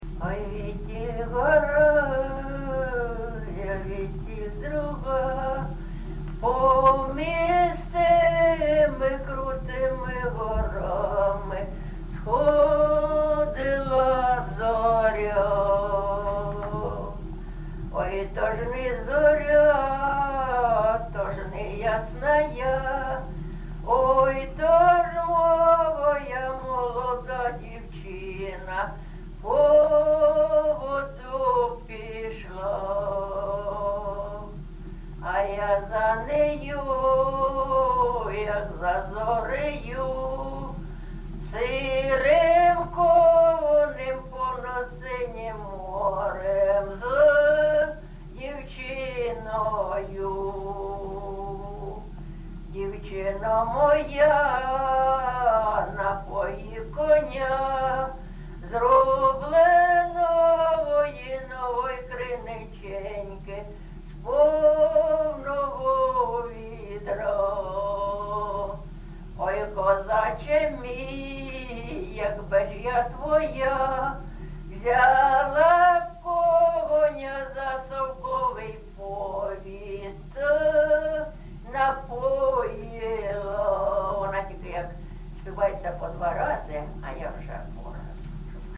ЖанрПісні з особистого та родинного життя
Місце записус. Софіївка, Краматорський район, Донецька обл., Україна, Слобожанщина